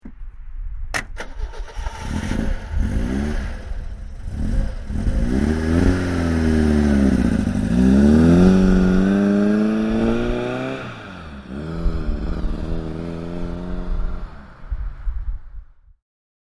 NSU TT (1971) - Starten/Losfahren (aussen)
NSU_TT_1971_-_Starten_und_Losfahren.mp3